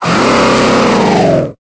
Cri de Sarmuraï dans Pokémon Épée et Bouclier.